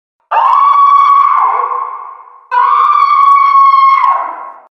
Крик при виде Момо
• Категория: Громкие крики
• Качество: Высокое